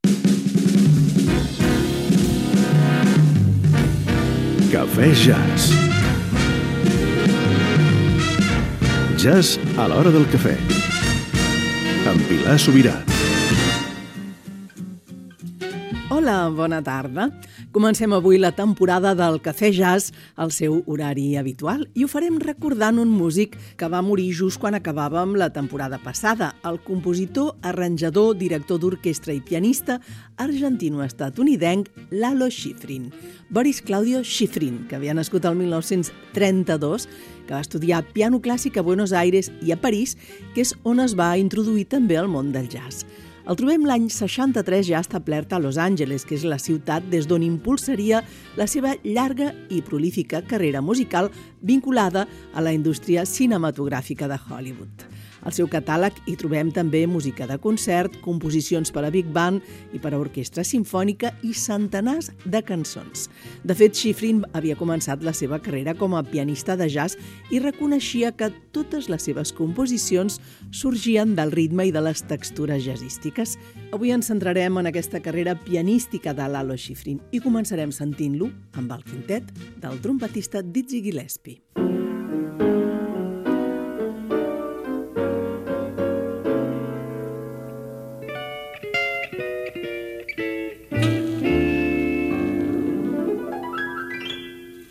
Careta del program. inici del primer programa de la temporada 2025-2026, dedicat al compositor Lalo Schifrin.
Musical